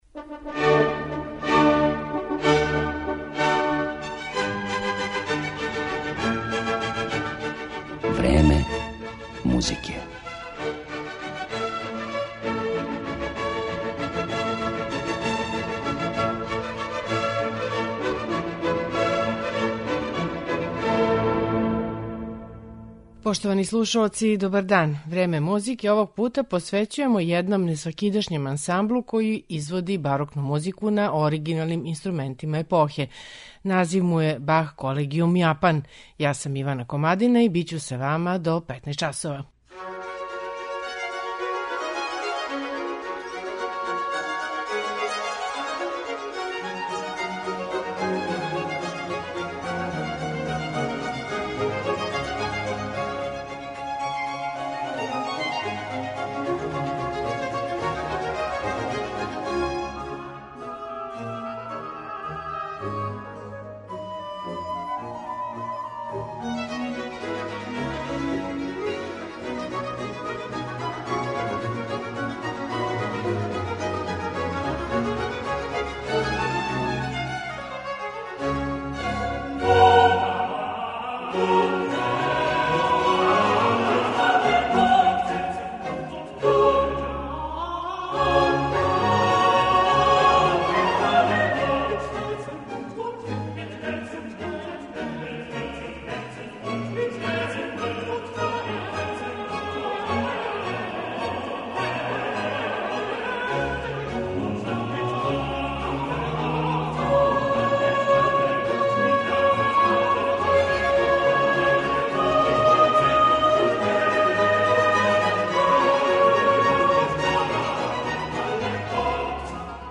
Необичној уметничкој судбини првог и, за сада, јединог јапанског ансамбла који користи оригиналне инструменте епохе посветили смо данашње Време музике . Поред Баха, тумачиће и дела Антонија Вивалдија, Дитриха Букстехудеа и Волфганга Амадеуса Моцарта.